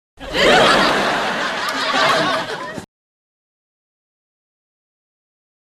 Laughs 5